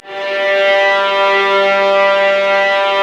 Index of /90_sSampleCDs/Roland LCDP08 Symphony Orchestra/STR_Vas Bow FX/STR_Vas Sul Pont